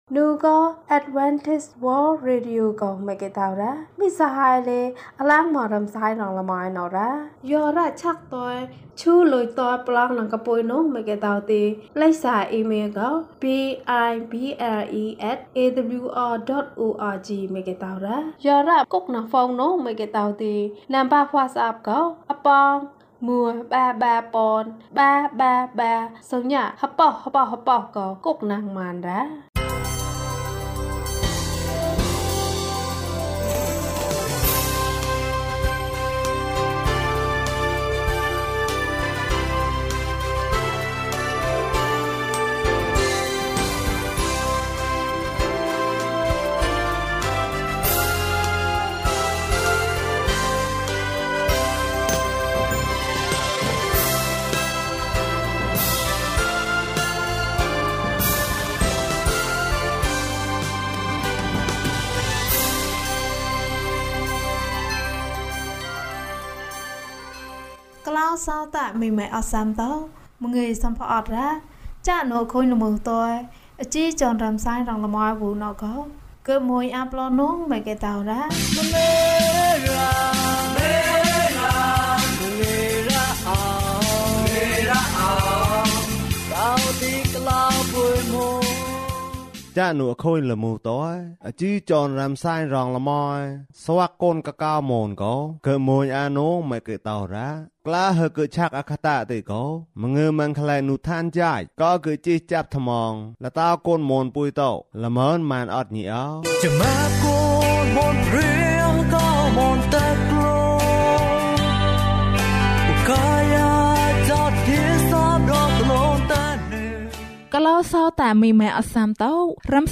ကောင်လေးနှင့်ခွေးကလေး။၀၁ ကျန်းမာခြင်းအကြောင်းအရာ။ ဓမ္မသီချင်း။ တရားဒေသနာ။